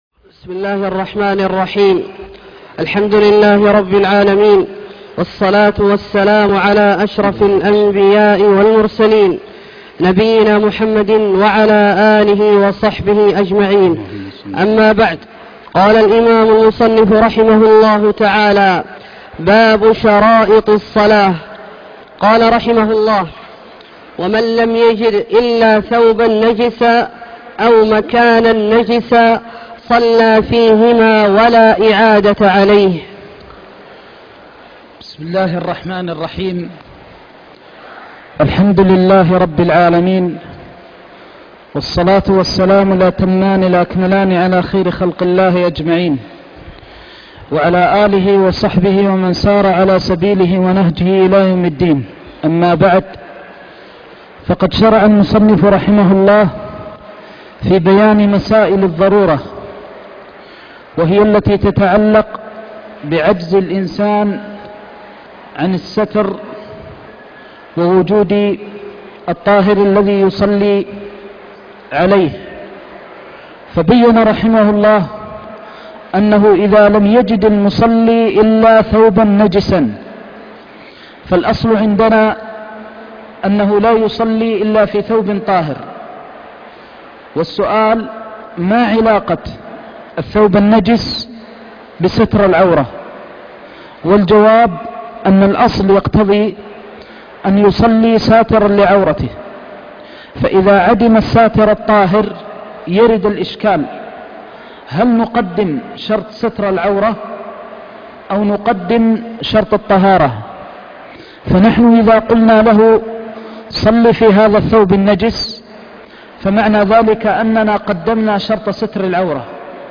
درس عمدة الفقه (4) - تابع كتاب الصلاة